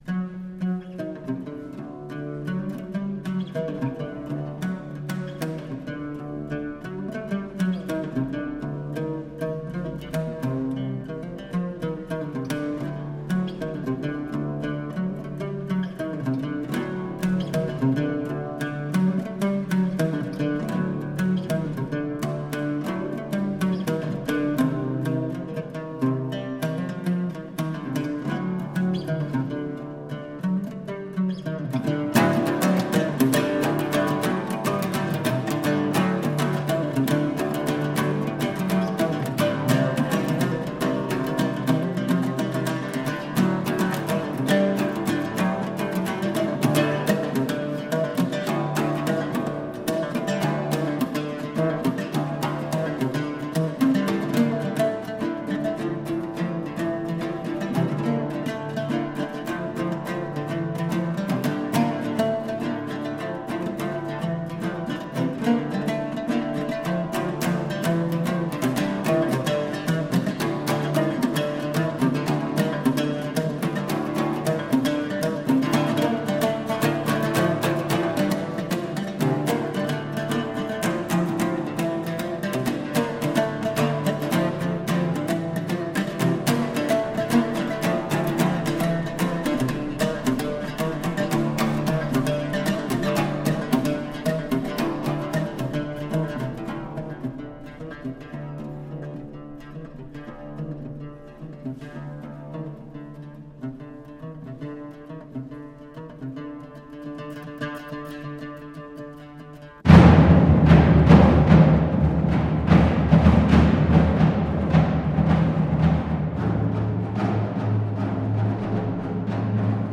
La visione dei Turchi di Maometto II, la paura dei Greci e dei Latini, le conseguenze della caduta della Città nella storia europea e mondiale, vengono presentate attraverso la musica di Dufay, Busnoys, la musica dei Jannizzeri e i canti dei Bizantini, in un perfetto quadro in cui il fragore delle armi risuonerà tanto quanto il silenzio della morte e devastazione che ne conseguirà, unito allo sbigottimento di tutta la Cristianità.